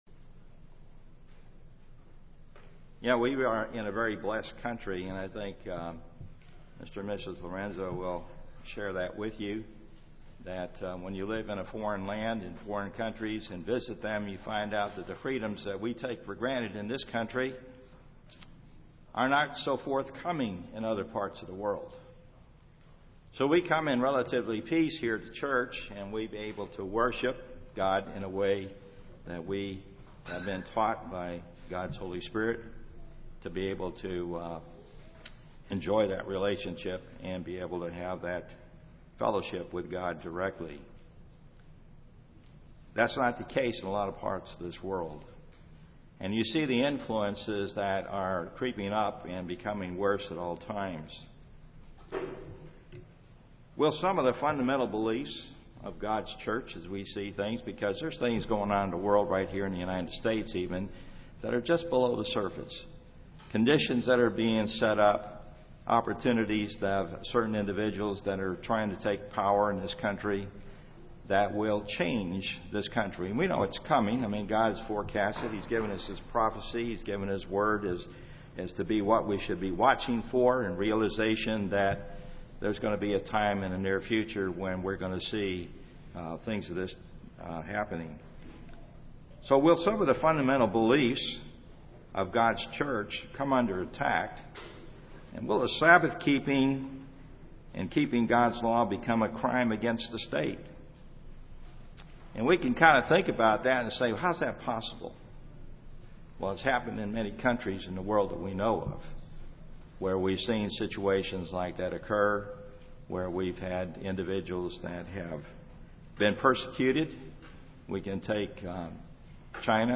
Given in Dallas, TX
UCG Sermon Studying the bible?